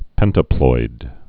(pĕntə-ploid)